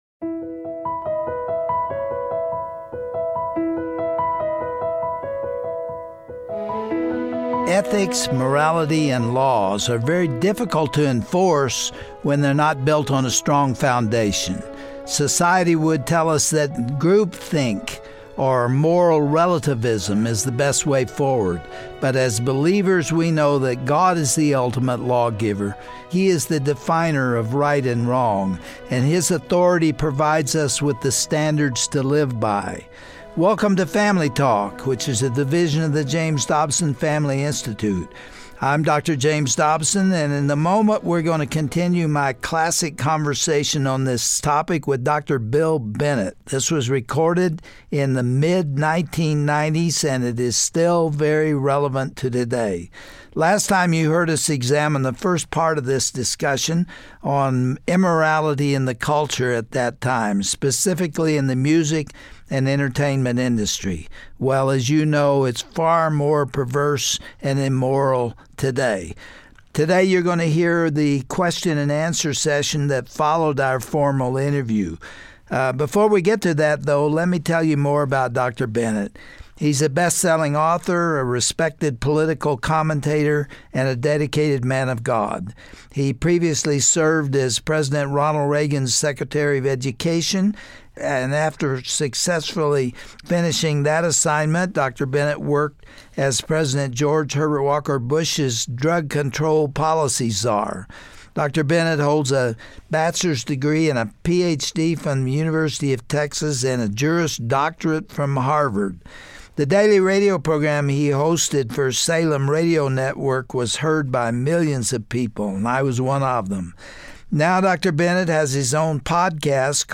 It's futile to try to establish societal ethics and laws without acknowledging God as the ultimate lawgiver. Dr. James Dobson and Dr. Bill Bennett answer questions from the studio audience, along with explaining why immorality is more rampant today than ever before, and imploring America to return to promoting godliness and family values.